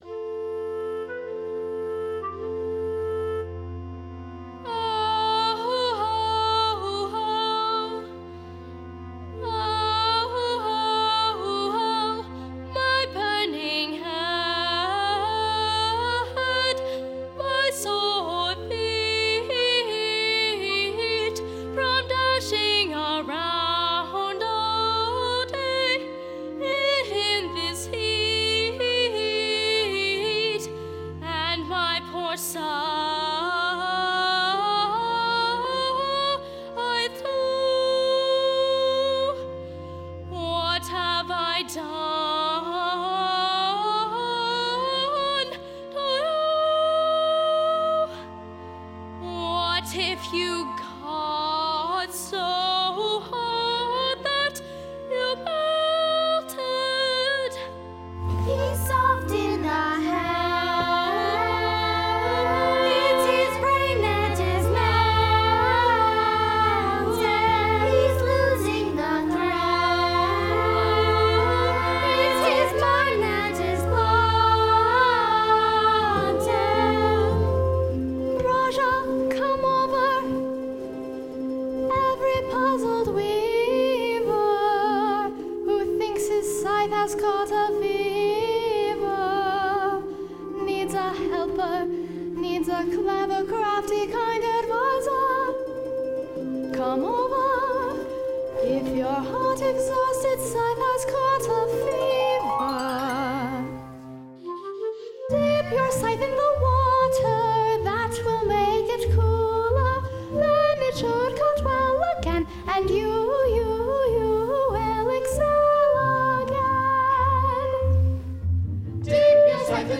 Performed by two intergenerational casts at:
The Feverish Scythe sung by Rajah, Jackal, the Kids, and the chorus